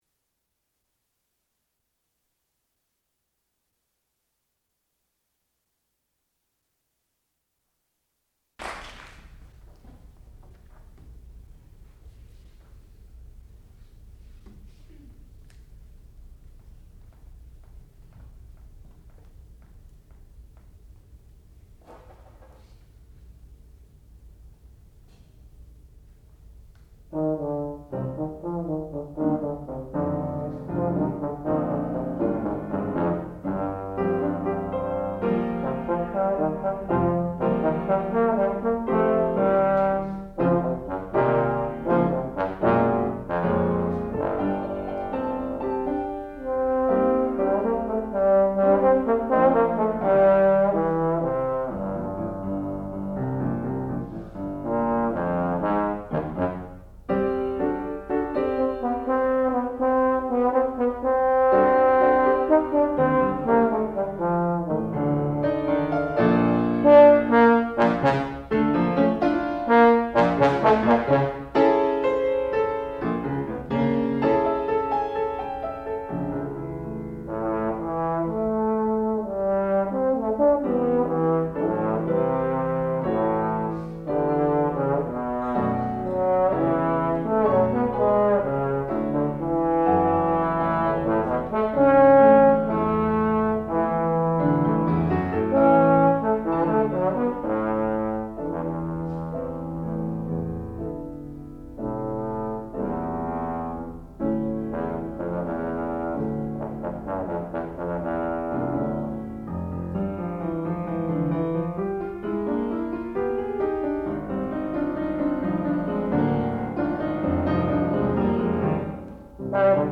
classical music
Master Recital
bass trombone